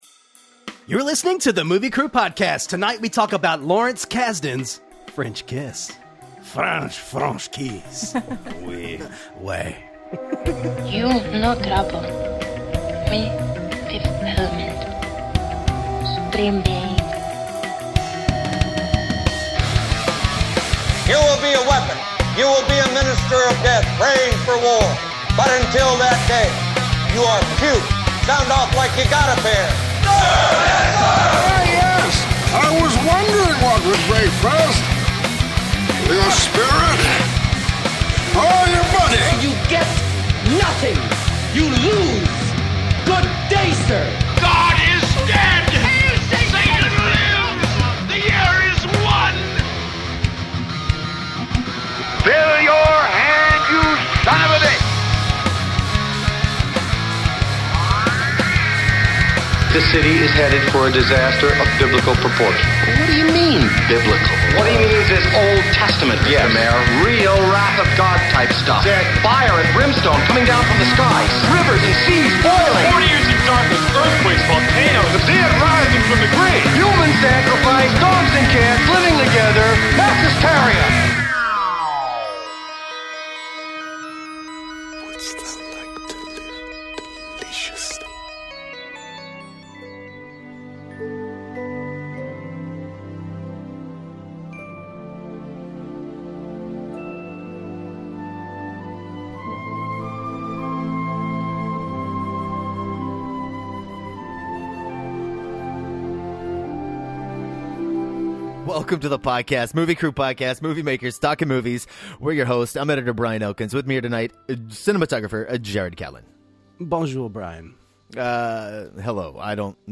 Filmmakers talking about movies because... Well, they would be doing it anyway!